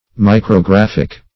Micrographic \Mi`cro*graph"ic\